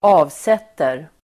Uttal: [²'a:vset:er]